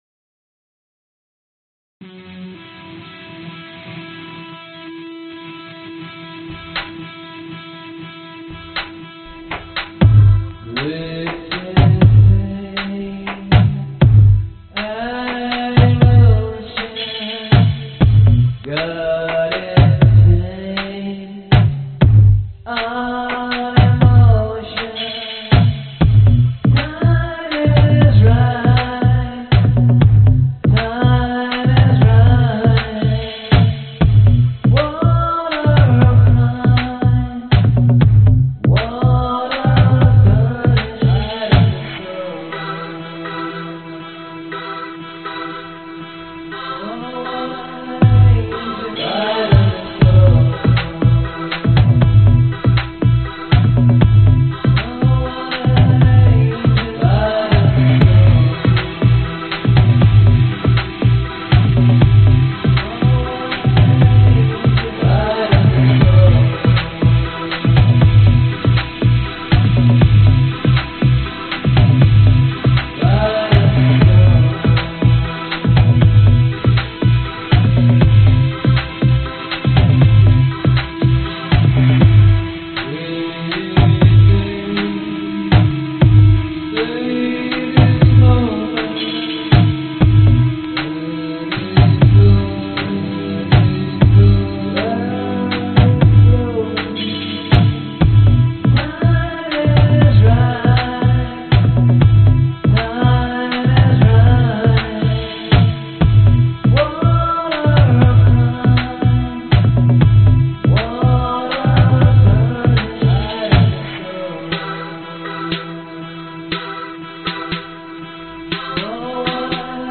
标签： 民谣 贝司 吉他 男声 钢琴
声道立体声